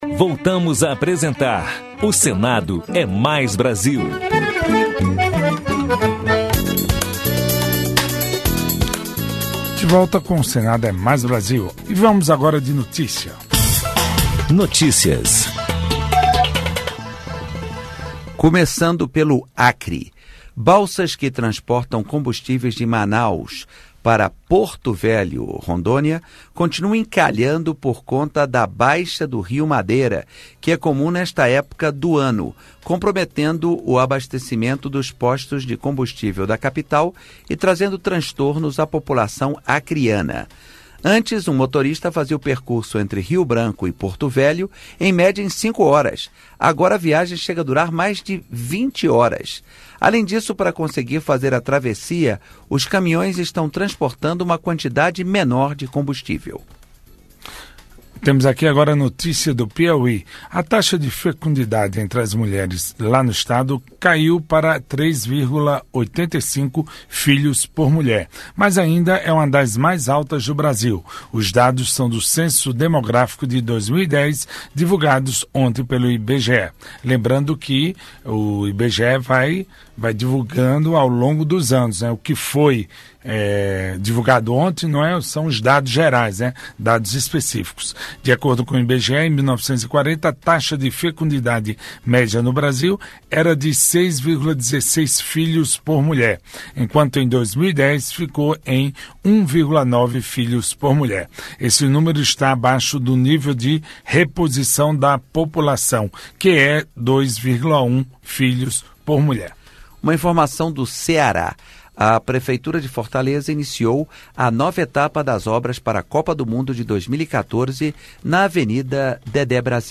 Notícias: giro pelos estados
Jorge Viana (PT-AC) fala sobre proposta que altera as regras de funcionamento das Zonas de Processamento de Exportações, as ZPEs Som Brasilis: Native Brazilian Music